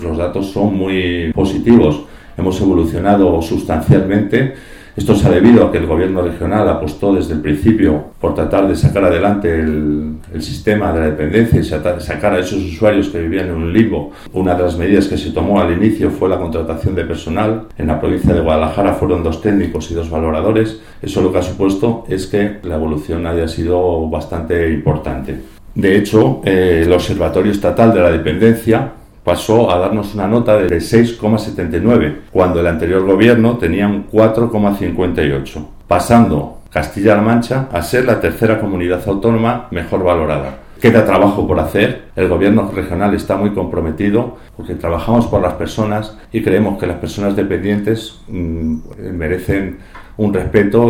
El director provincial de Bienestar Social, José Luis Vega, habla del aumento de personal destinado en la provincia de Guadalajara al servicio de atención a la dependencia y de como ha contribuido este hecho a reducir el limbo de la dependencia.